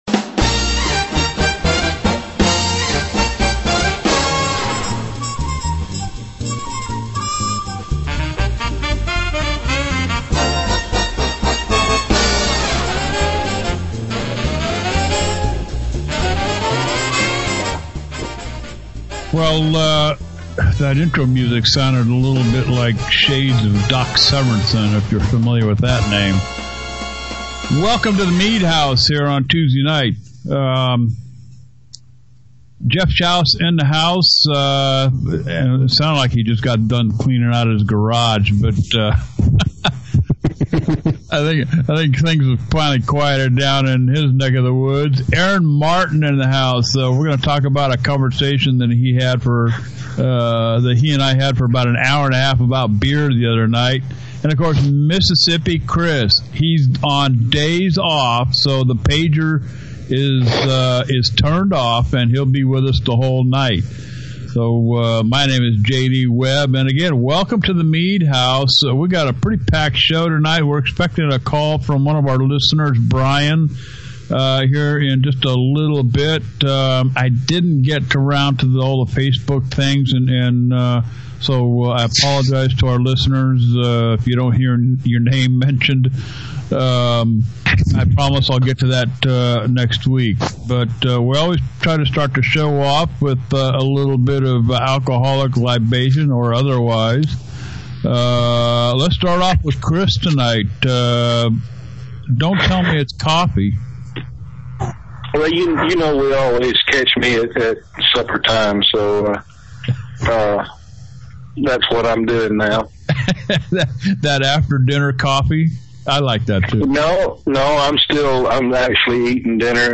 gives us a call, had some questions on oaking and PH.